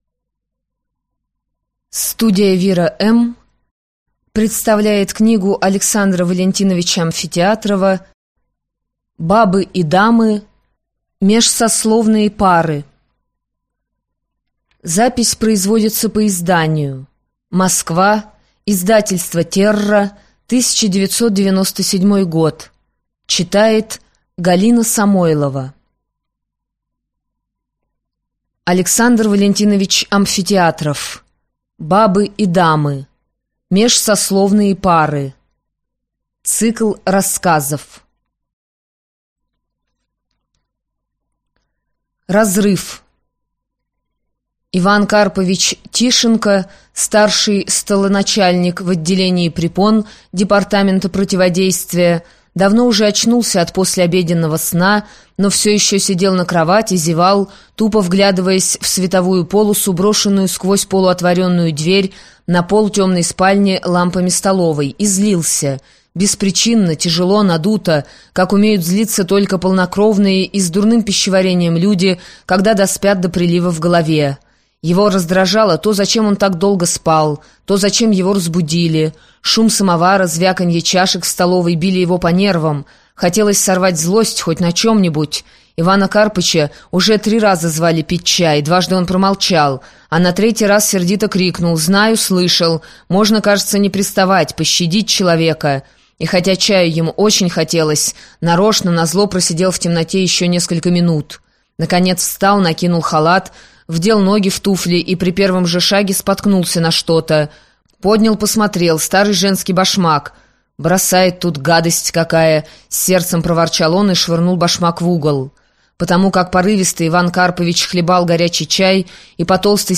Аудиокнига Бабы и дамы (Цикл рассказов) | Библиотека аудиокниг